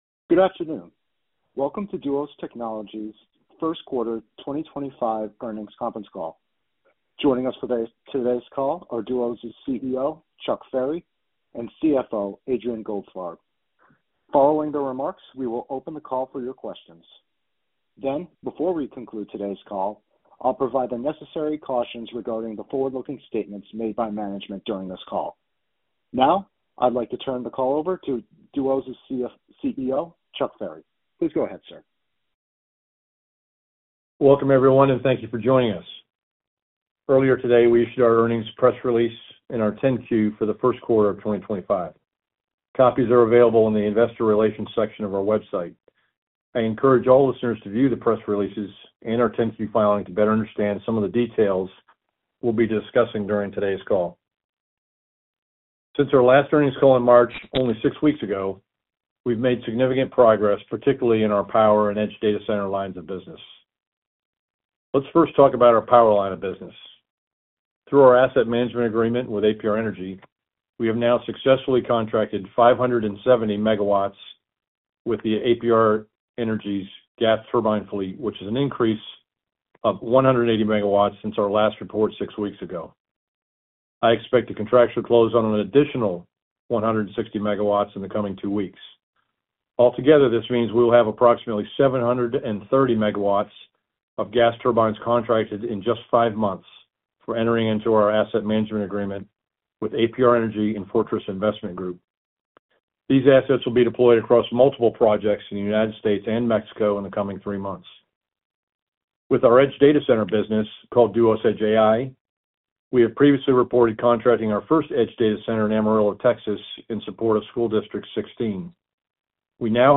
First Quarter 2025 Financial Results Conference Call